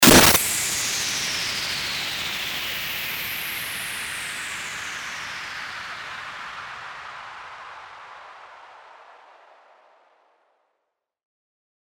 FX-1902-STARTER-WHOOSH
FX-1902-STARTER-WHOOSH.mp3